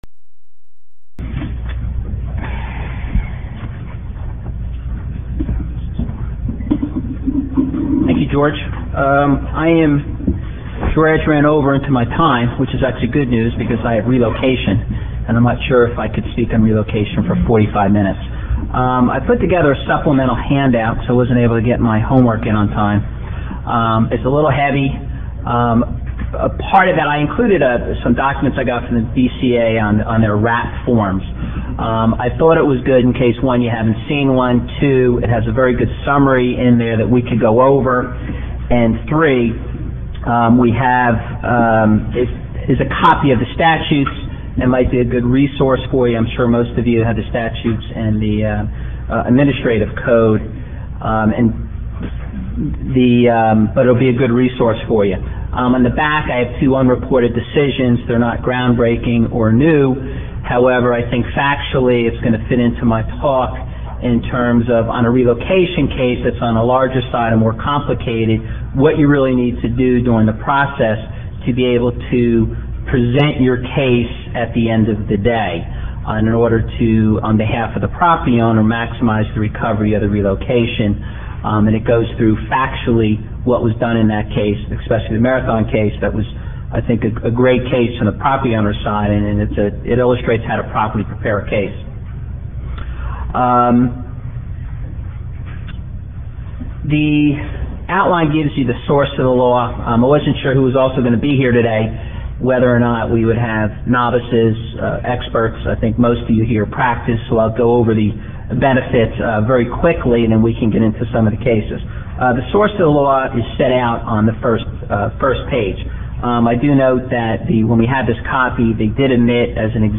Relocation-Benefits-ICLE-Eminent-Domain-Conferen.mp3